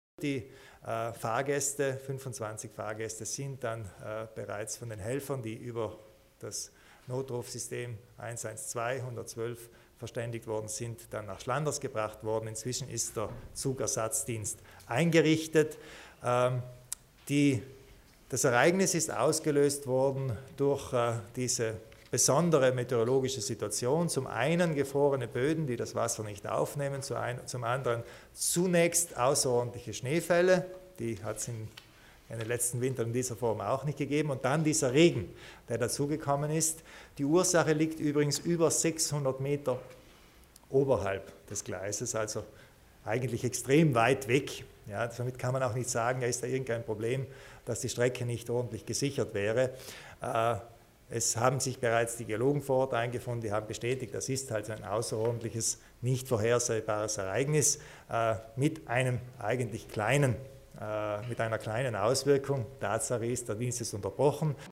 Landeshauptmann Kompatscher zur Mure auf der Vinschger Bahn